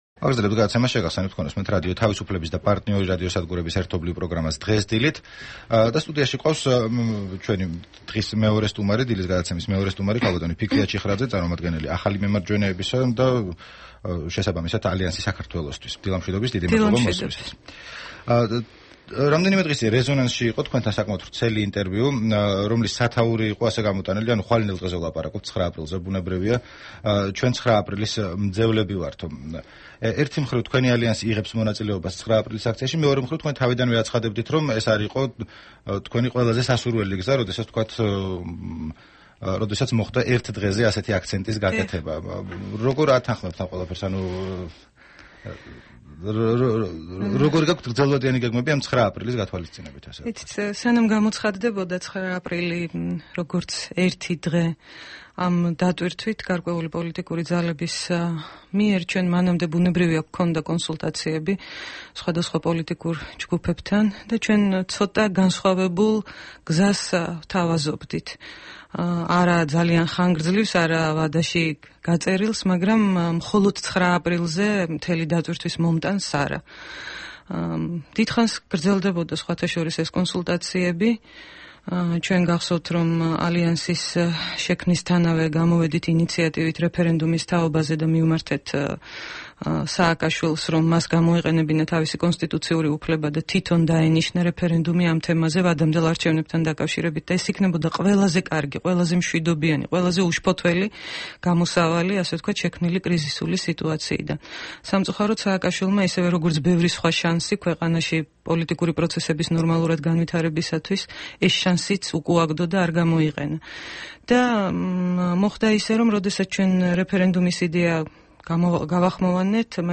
სტუმრად ჩვენს ეთერში - ფიქრია ჩიხრაძე